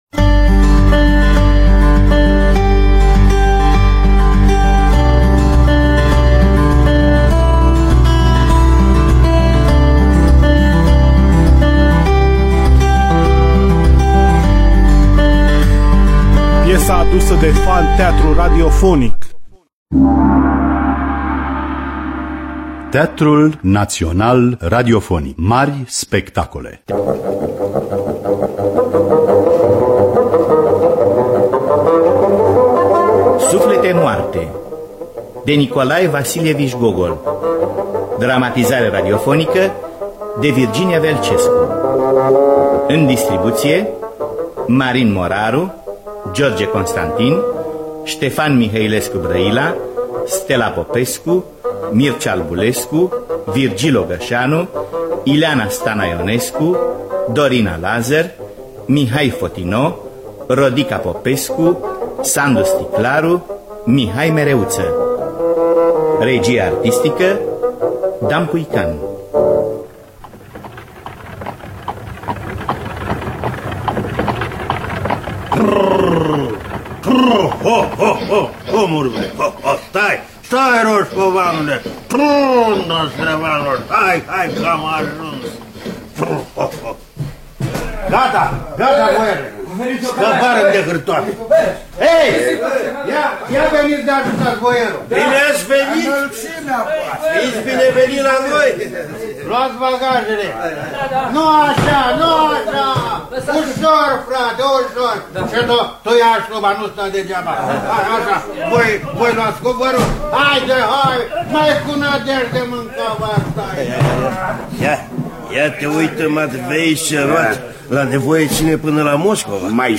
“Suflete goale” de Nikolai Vasilievici Gogol – Teatru Radiofonic Online
Dramatizare de Virginia Velcescu.